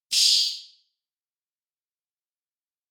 Player ship has door with open/close sfx
space_door.wav